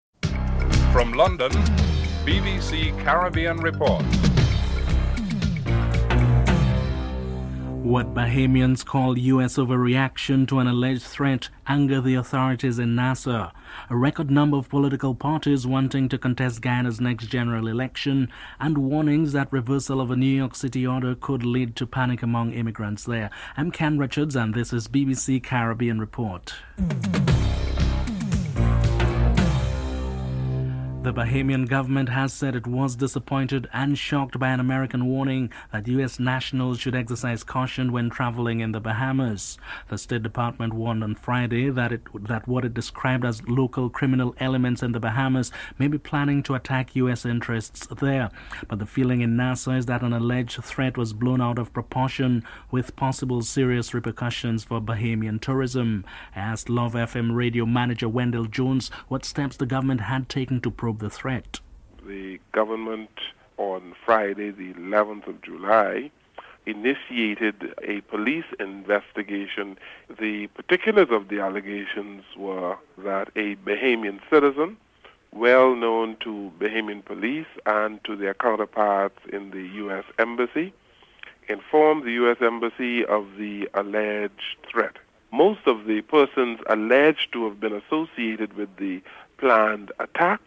1. Headlines (00:00-29)